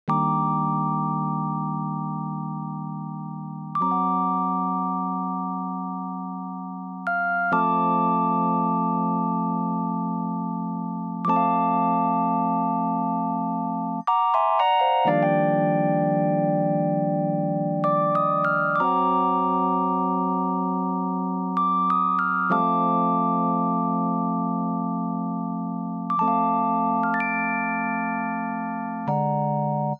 07 rhodes A.wav